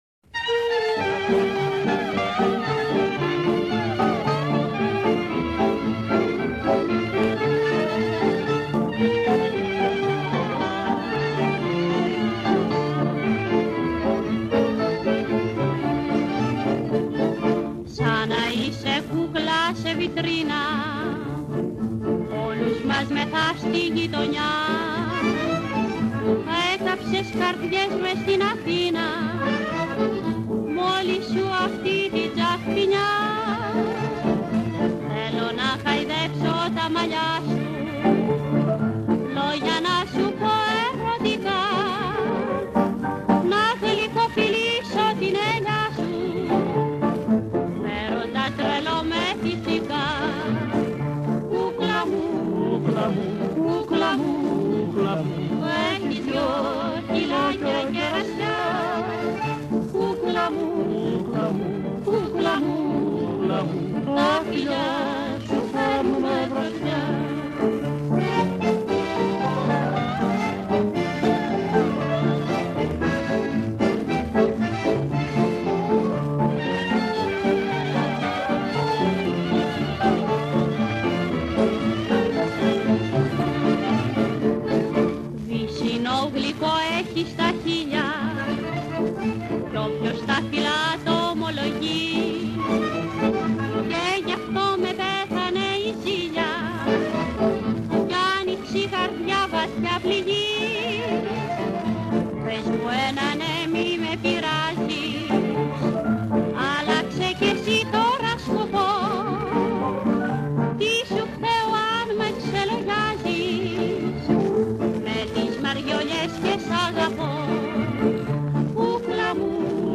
(качество улучшенное)